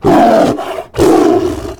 CosmicRageSounds / ogg / general / combat / creatures / tiger / he / prepare1.ogg